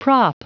Prononciation du mot prop en anglais (fichier audio)
Prononciation du mot : prop